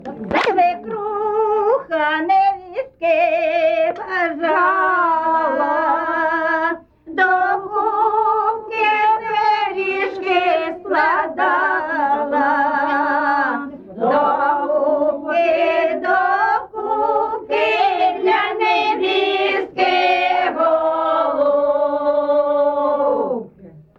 ЖанрВесільні
Місце записус. Шарівка, Валківський район, Харківська обл., Україна, Слобожанщина